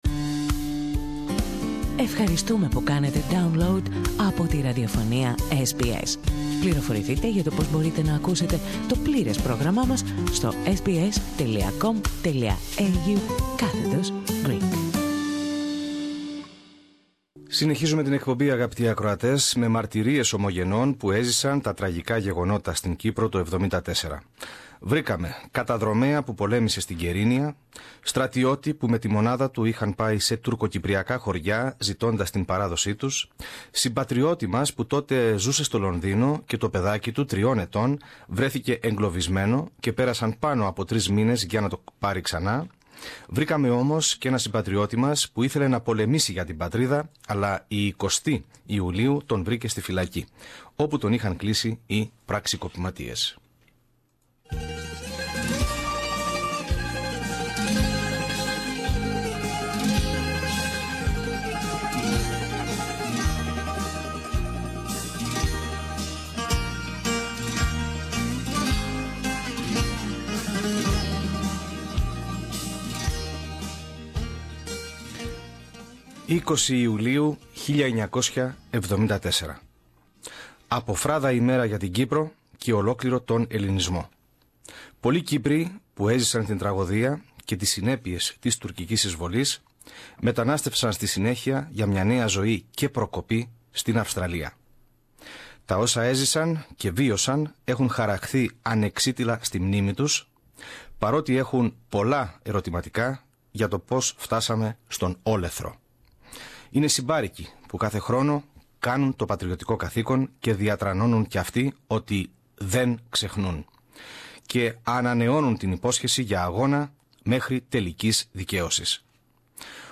Το πρόγραμμά μας σημαδεύει την επέτειο με μαρτυρίες ομογενών που έζησαν τα τραγικά γεγονότα.